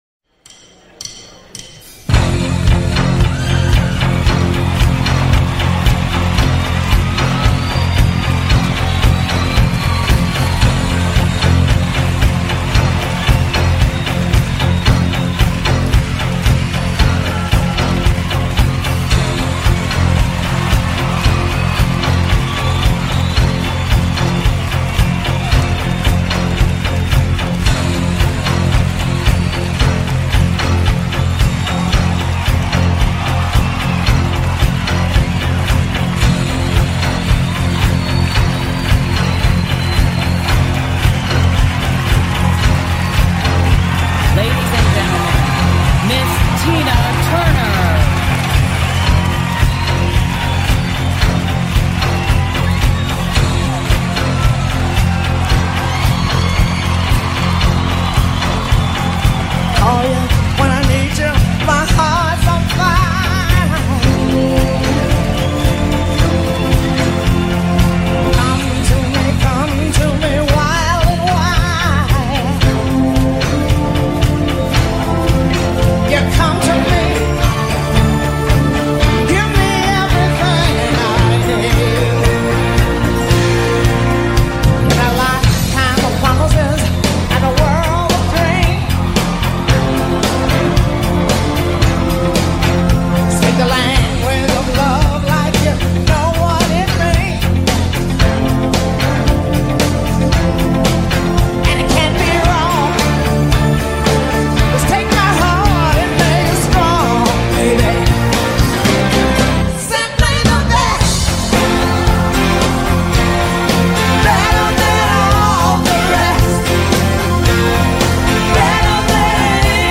#80smusic